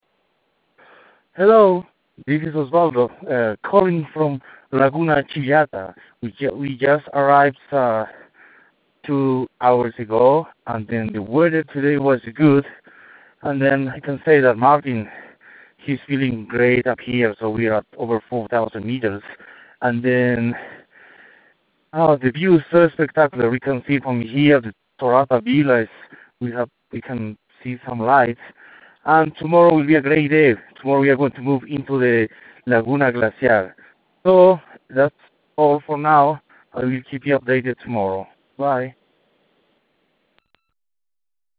Bolivia Expedition Dispatch